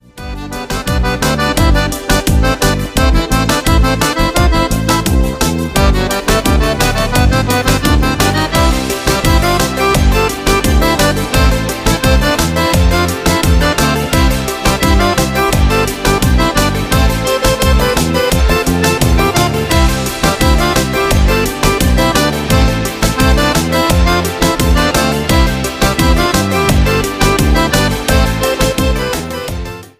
KIZOMBA  (03.34)